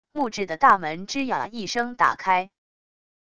木质的大门吱呀一声打开wav音频